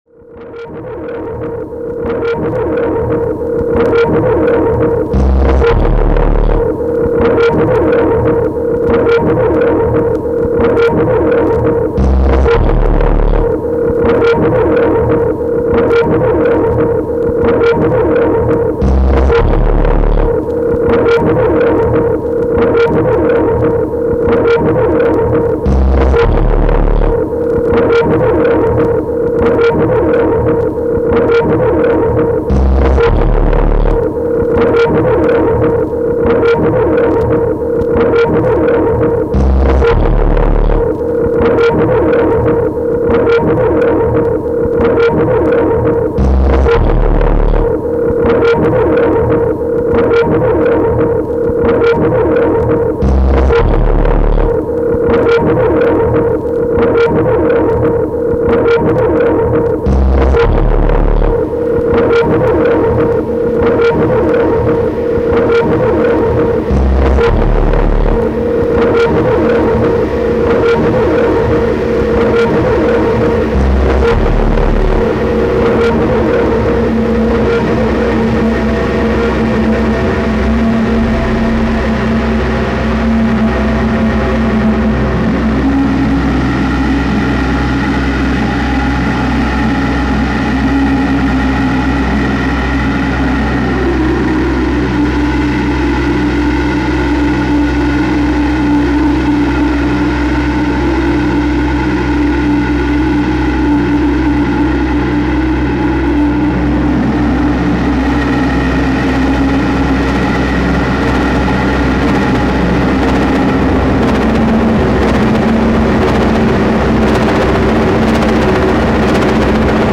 reading
music soundtrack and the text.